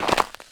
pedology_ice_white_footstep.3.ogg